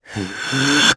Arch-Vox_Casting2_jp.wav